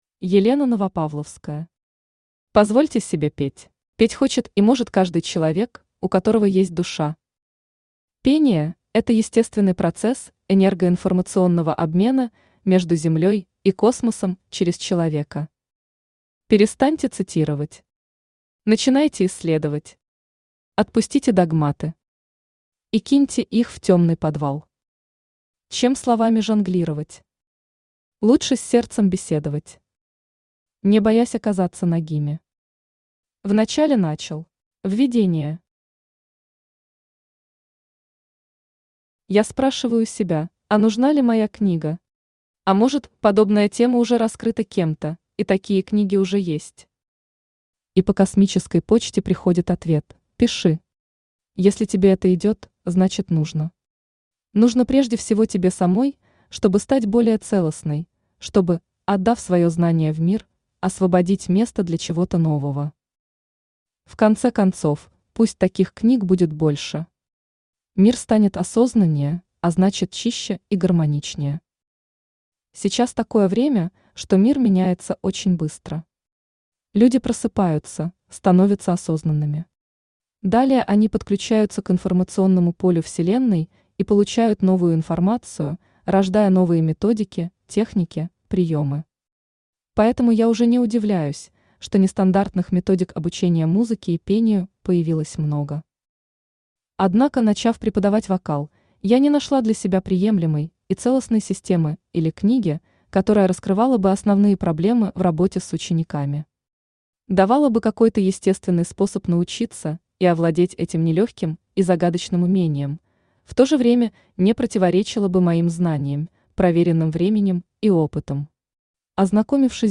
Аудиокнига Позвольте себе петь!
Автор Елена Новопавловская Читает аудиокнигу Авточтец ЛитРес.